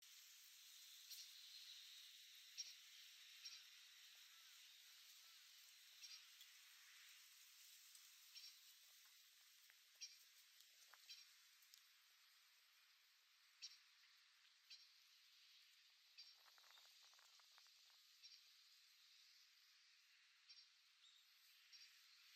広報さがみはら令和5年2月1日号では、本市から横浜市までを結ぶ横浜水道道のうち、市内緑区から南区までのハイキングコースを紹介しています。今回の紙面を眺めながら、音声でもハイキングコースの雰囲気を楽しんでください。
音で楽しむ01 ジョギングコース（中央区田名）